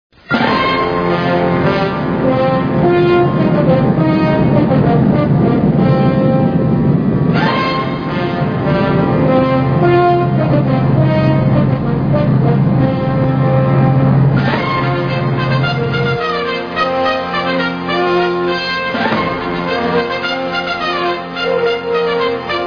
the themesong